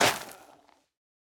Minecraft Version Minecraft Version 1.21.5 Latest Release | Latest Snapshot 1.21.5 / assets / minecraft / sounds / block / soul_soil / break5.ogg Compare With Compare With Latest Release | Latest Snapshot
break5.ogg